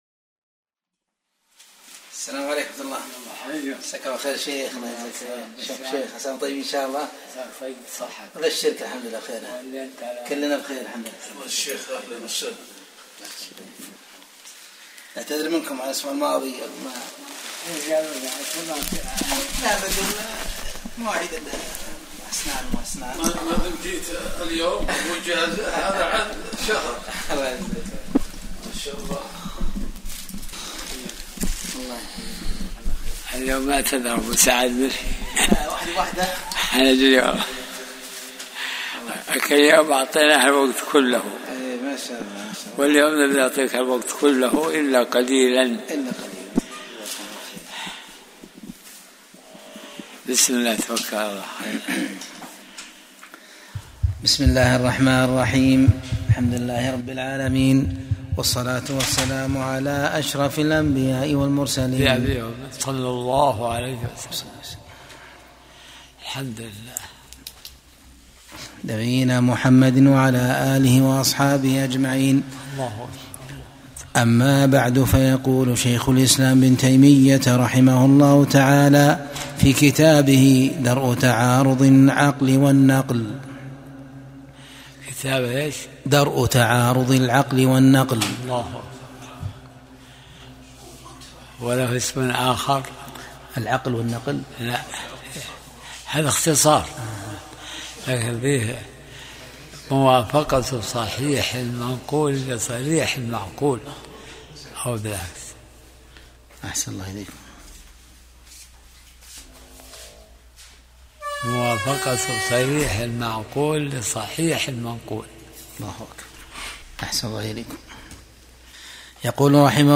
درس الأحد 67